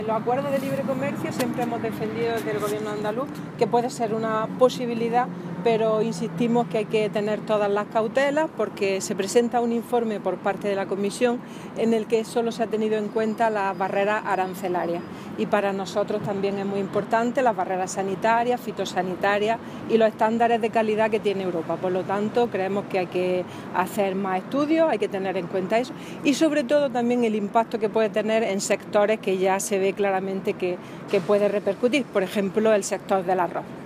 Declaraciones de Carmen Ortiz sobre acuerdos de libre comercio